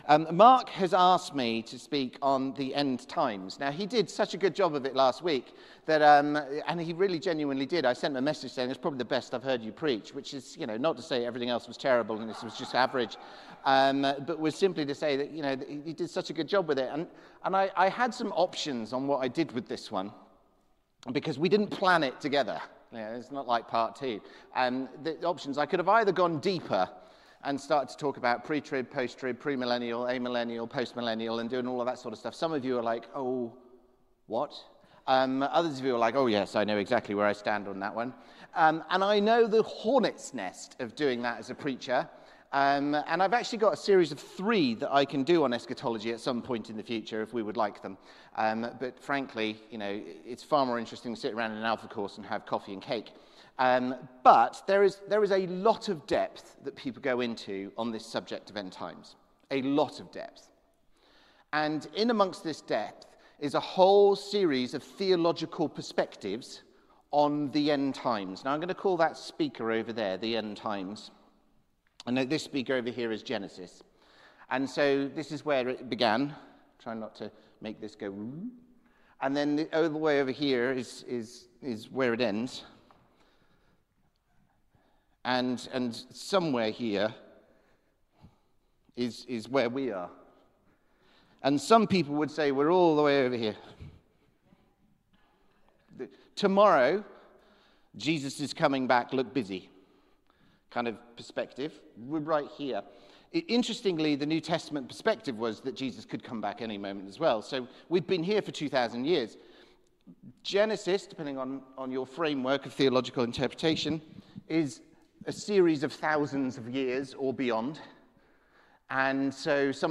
Sermon - Christ the Coming King: Part2